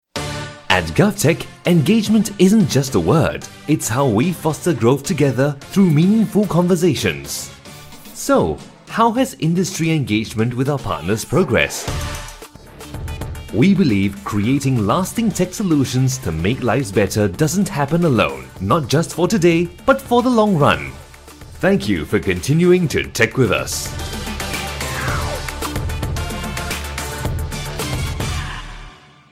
Male
English (Neutral - Mid Trans Atlantic)
A warm, clear, and authoritative Singaporean voice.
My tone ranges from a friendly, conversational guy-next-door to a highly trusted, serious professional.
I deliver broadcast-quality audio with excellent diction and pacing.